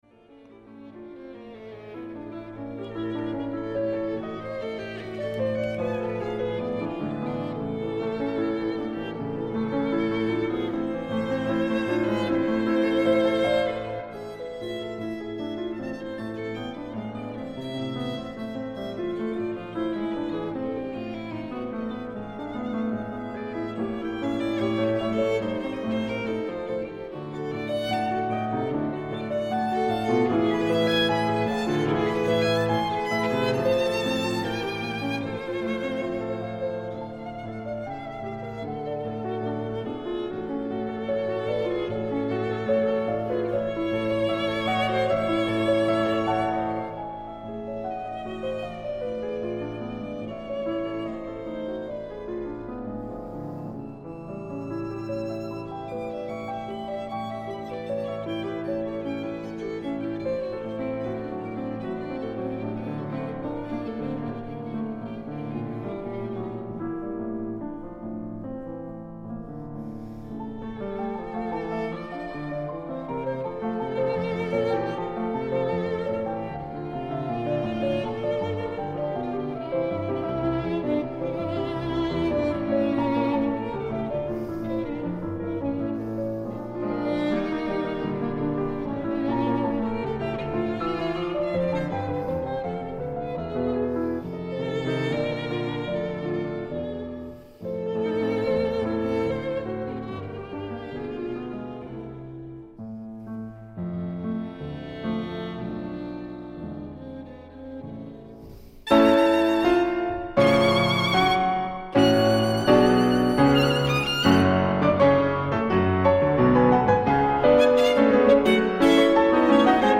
Musica popolare greca, canzoni di vari luoghi ed epoche legate da un tema unificante